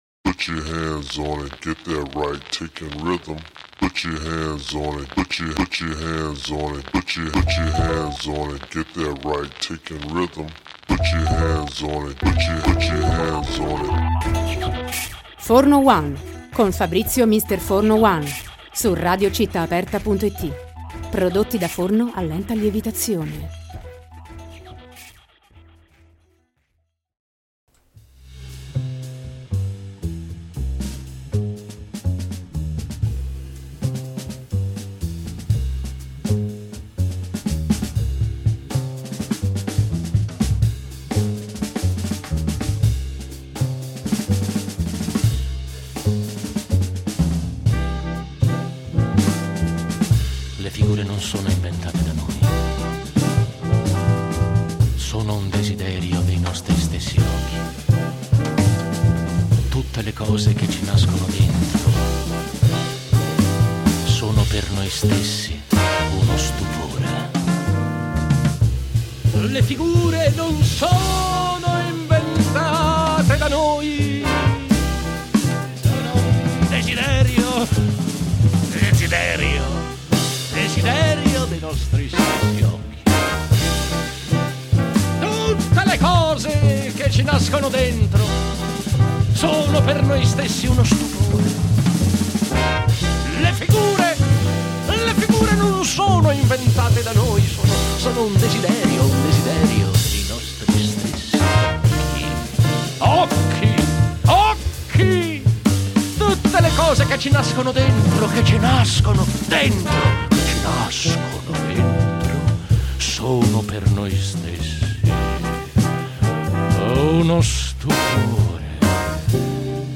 Così è (se vi pare): intervista